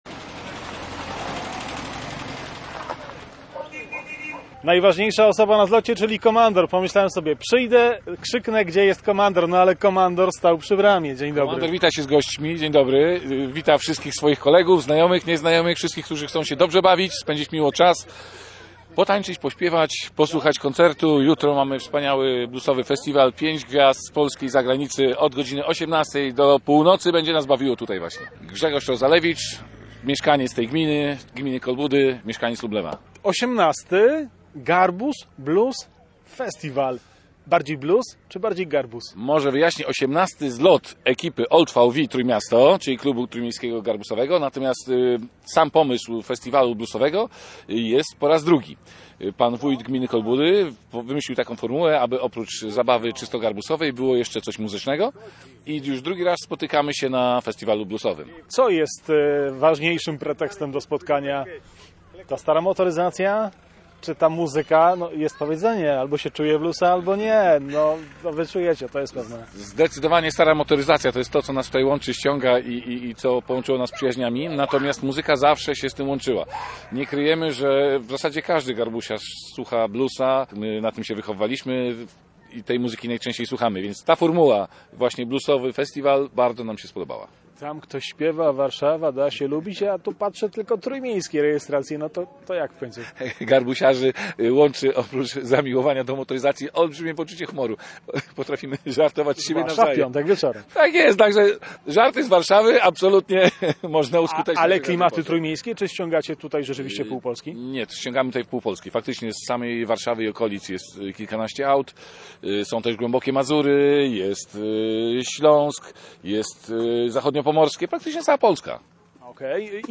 Kilkudziesięciu entuzjastów samochodu Volkswagen Garbus spotkało się pod koniec lipca w Kolbudach na imprezie pod nazwą “Garbus Blues Festiwal 2023”.